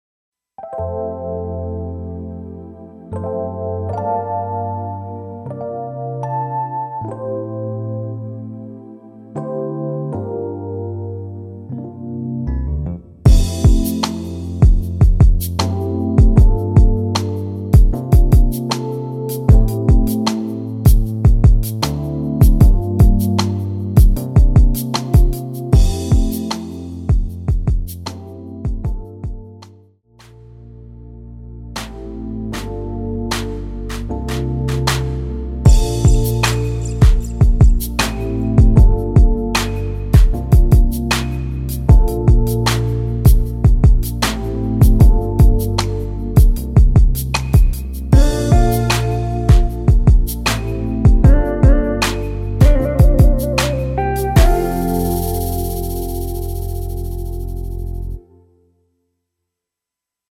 MR입니다.
엔딩이 너무 길어서 라이브 하시기 좋게 4마디로 편곡 하였습니다.(미리듣기 참조)
앞부분30초, 뒷부분30초씩 편집해서 올려 드리고 있습니다.
중간에 음이 끈어지고 다시 나오는 이유는